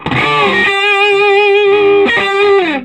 Index of /90_sSampleCDs/USB Soundscan vol.22 - Vintage Blues Guitar [AKAI] 1CD/Partition C/06-SOLO G125